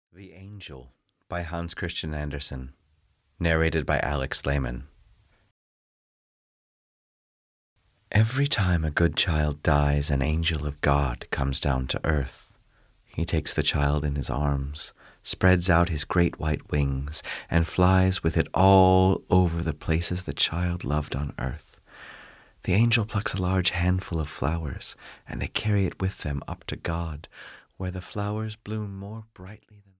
The Angel (EN) audiokniha
Ukázka z knihy